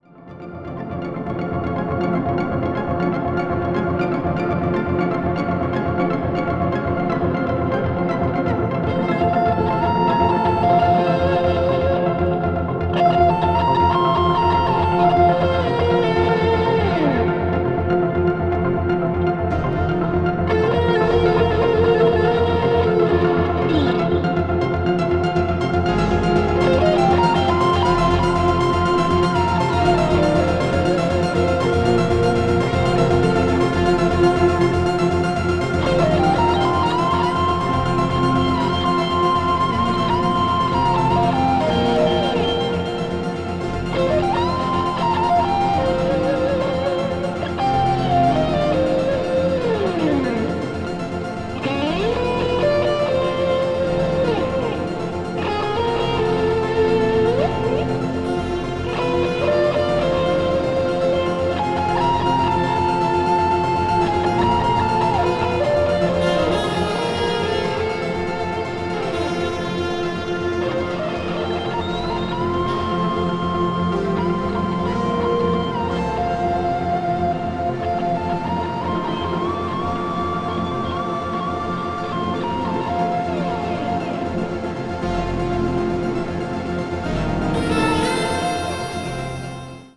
media : EX+/EX+(わずかにチリノイズが入る箇所あり)
art rock   electronic   progressive rock   synmthesizer